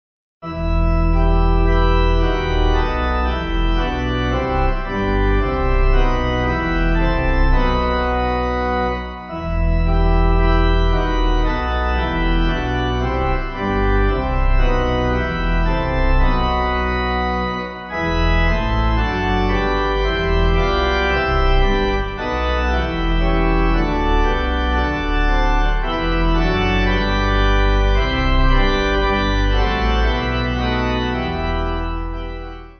Organ
(CM)   5/Em